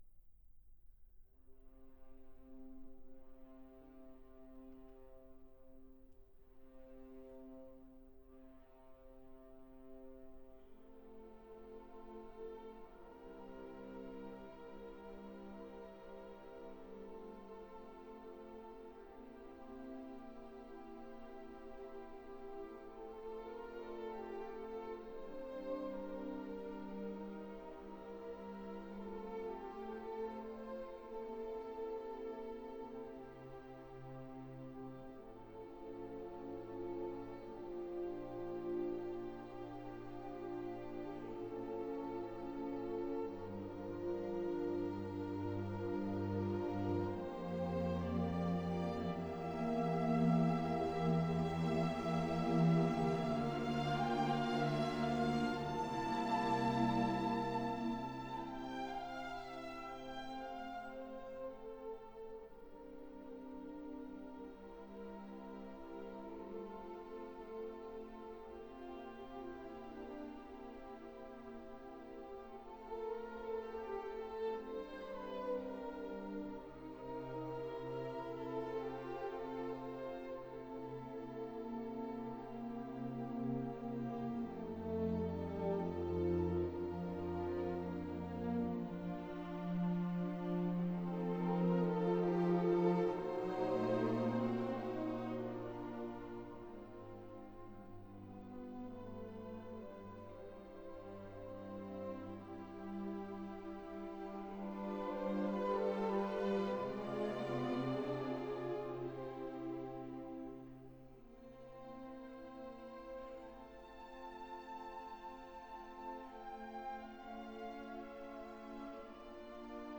Fauré - Masques et Bergamasques, Suite for orchestra Op. 112